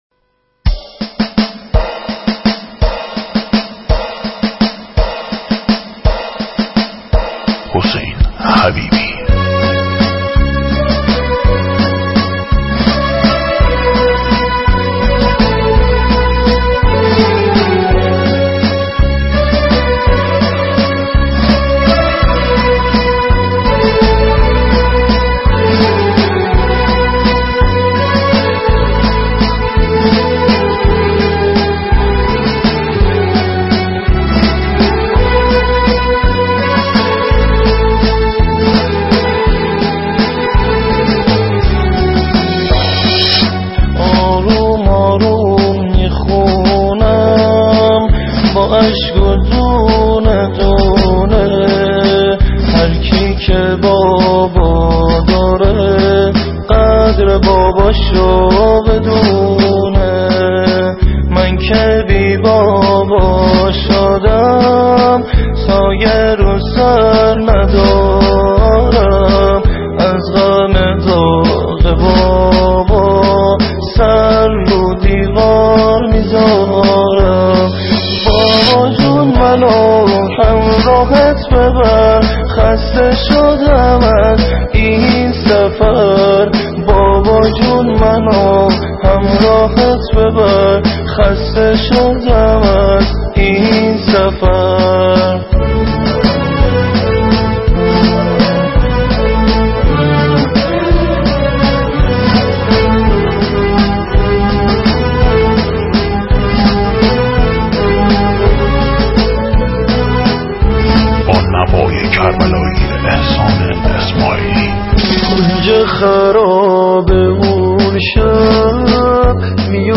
خوانندگان مازنی